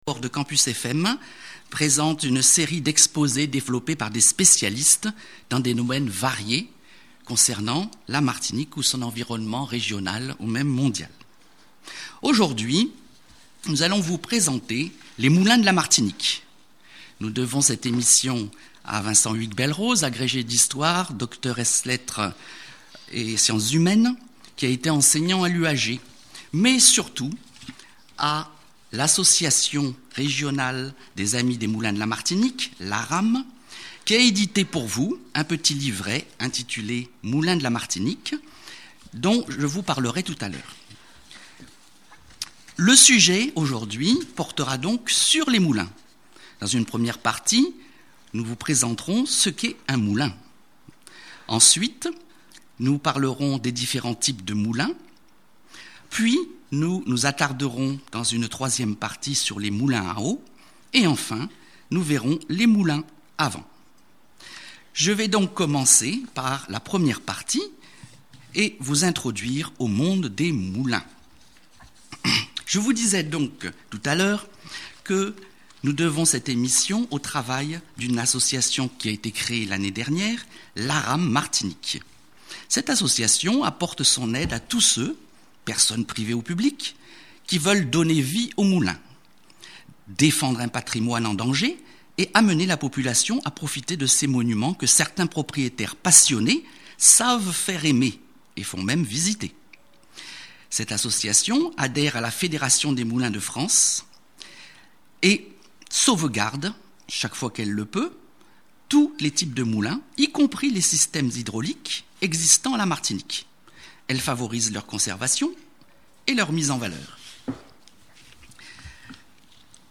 Émission radiophonique